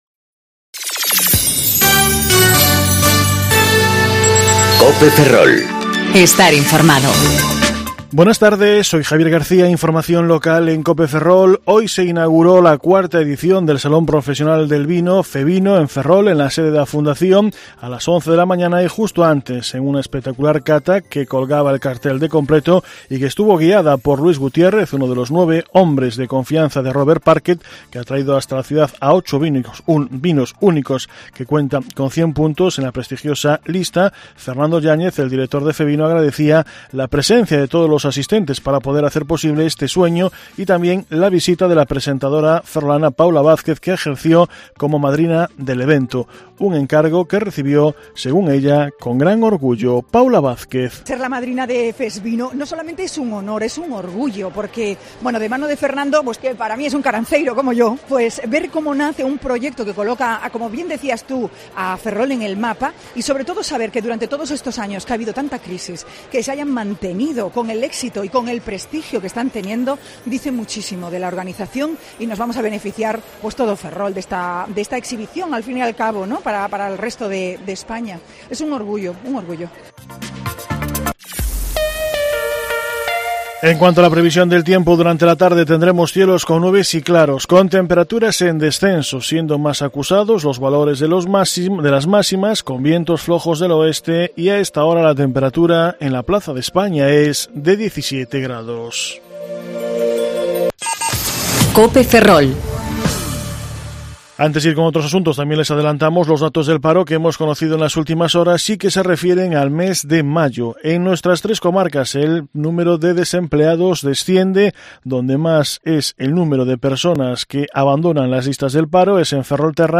Informativo Mediodía Cope Ferrol 4/06/2019 (De 14.20 a 14.30 horas)